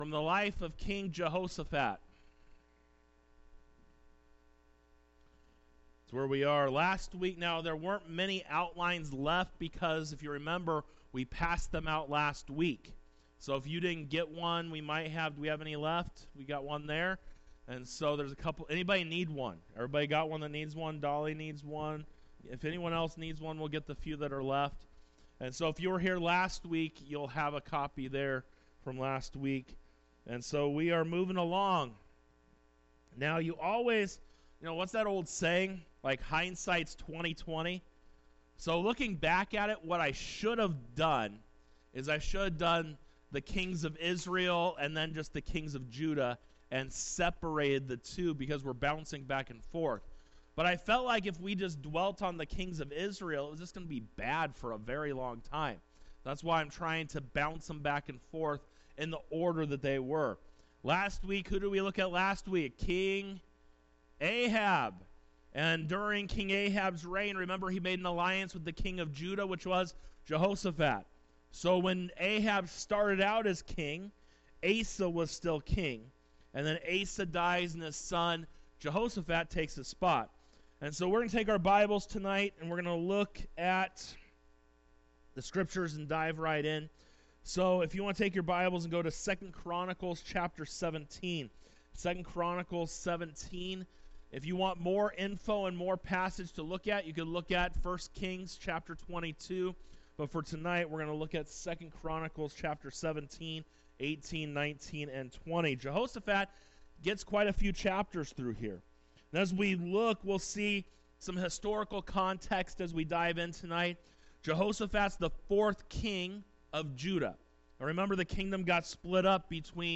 Sermons | Victory Baptist Church
Wednesday Bible Study 02:12:25